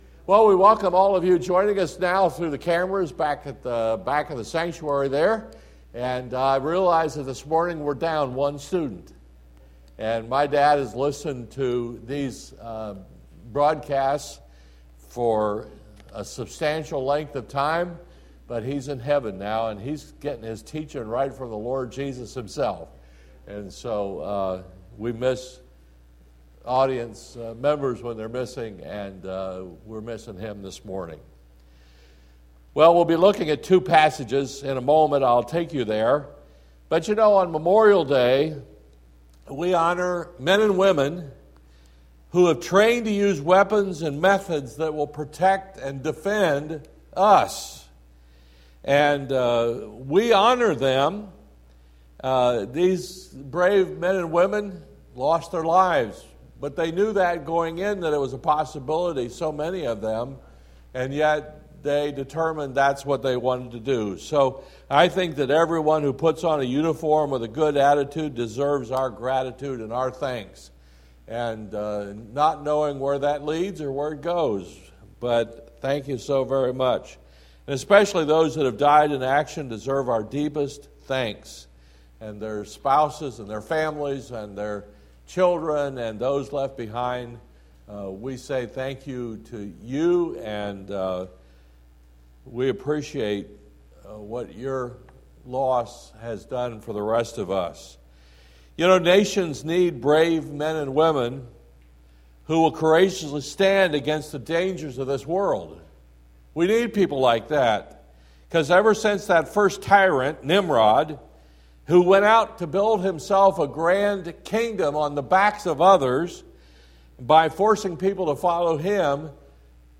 Sermon